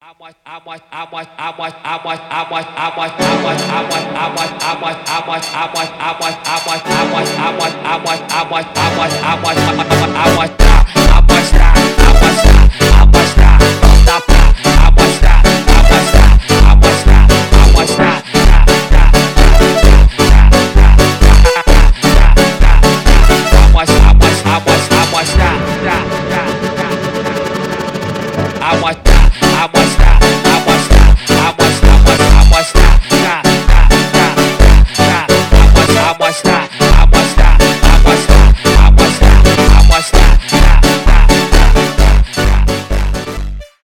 фанк , танцевальные , фонк